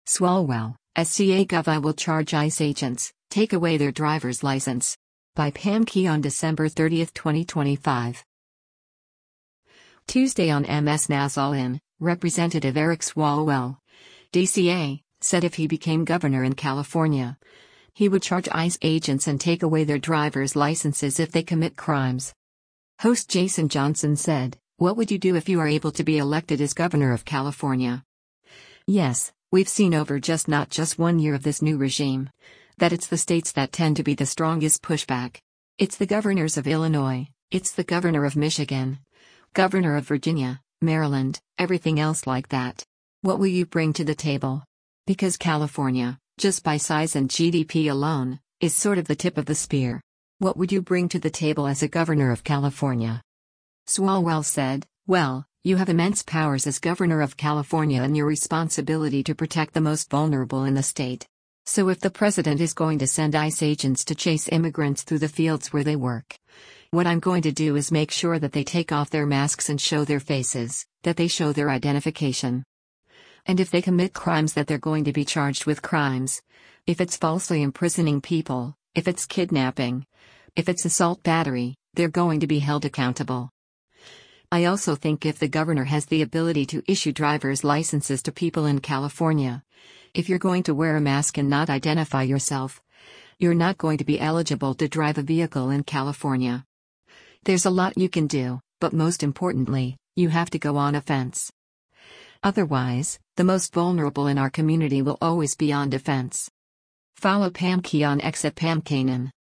Tuesday on MS NOW’s “All In,” Rep. Eric Swalwell (D-CA) said if he became governor in California, he would charge ICE agents and take away their driver’s licenses if they commit crimes.